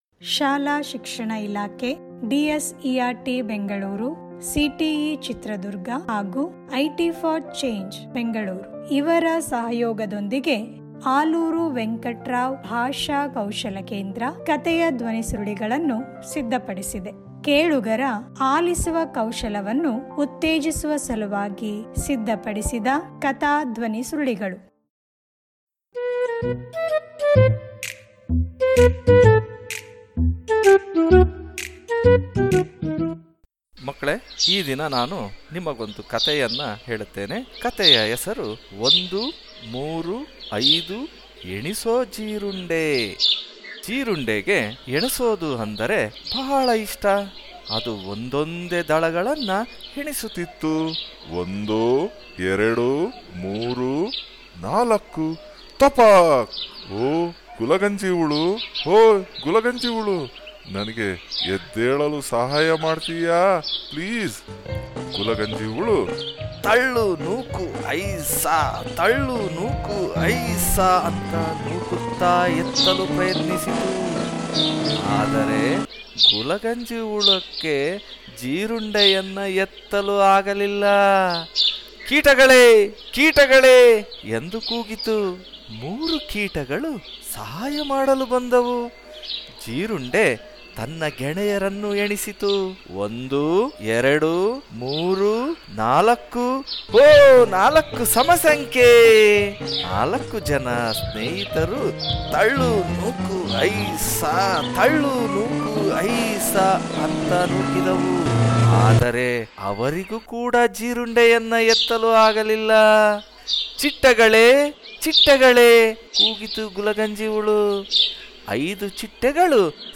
ಒಂದು ಮೂರು ಐದು ಎಣಿಸು ಜೀರುಂಡೆ - ಧ್ವನಿ ಕಥೆಯ ಚಟುವಟಿಕೆ ಪುಟ